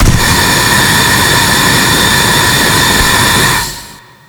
gas_ability.wav